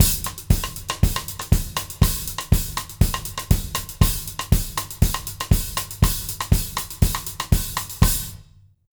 120ZOUK 02-L.wav